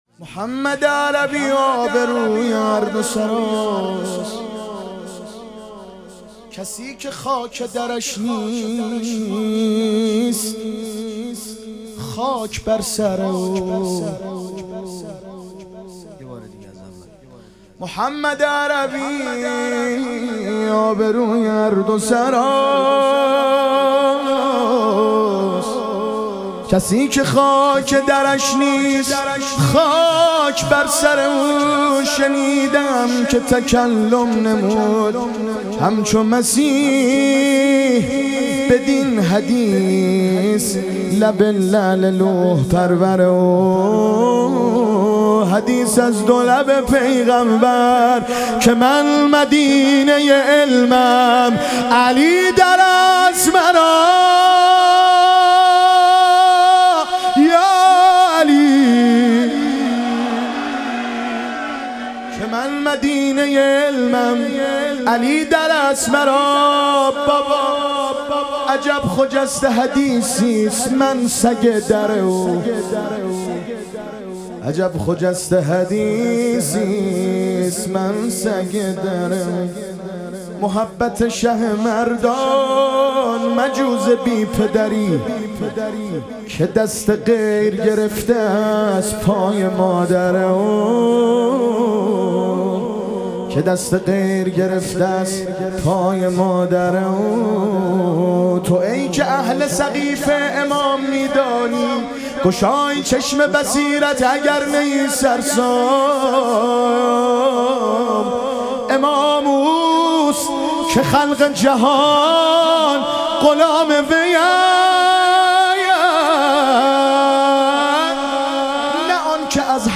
ظهور وجود مقدس رسول اکرم و امام صادق علیهم السلام - مدح و رجز